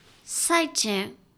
ボイス
女性挨拶